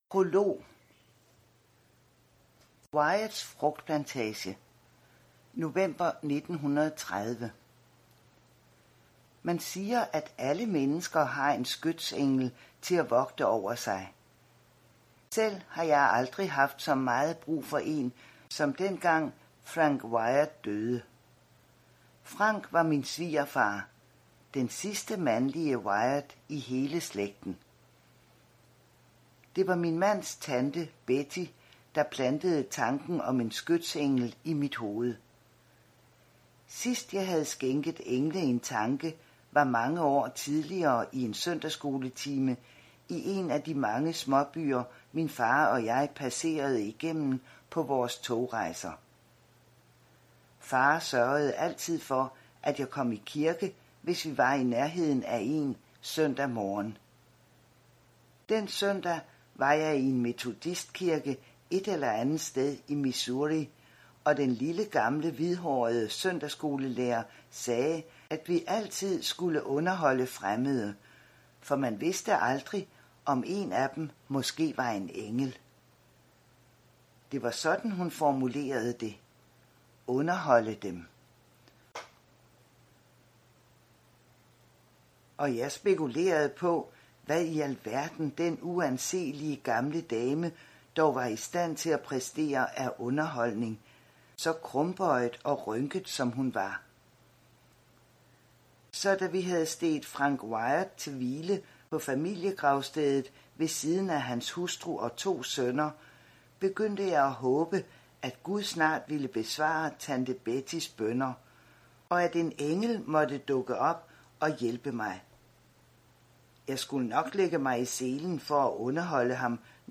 Hør et uddrag af I det skjulte I det skjulte Format MP3 Forfatter Lynn Austin Bog Lydbog E-bog 249,95 kr.